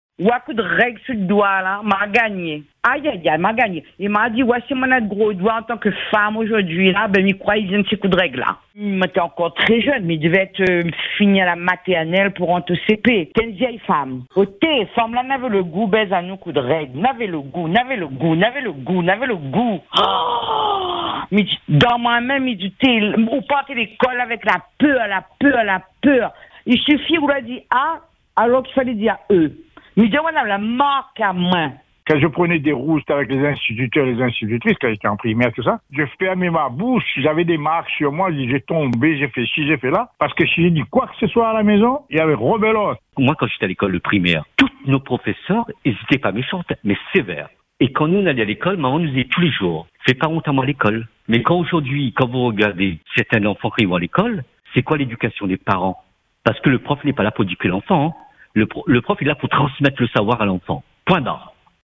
Voici un medley de vos témoignages.